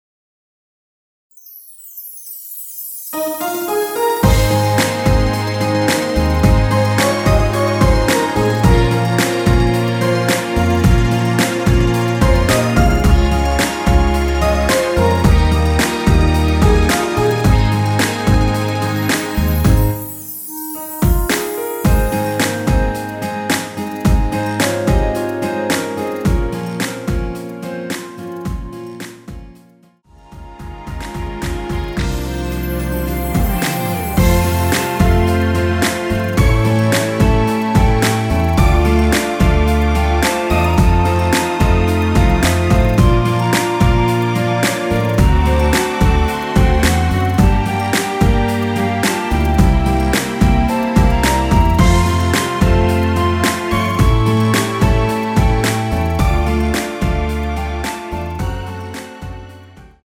원키에서(-1)내린 멜로디 포함된 MR입니다.
전주가 너무길어 시작 Solo 부분22초 정도 없이 제작 하였으며
엔딩부분이 페이드 아웃이라 엔딩을 만들어 놓았습니다.(미리듣기 참조)
Ab
앞부분30초, 뒷부분30초씩 편집해서 올려 드리고 있습니다.
(멜로디 MR)은 가이드 멜로디가 포함된 MR 입니다.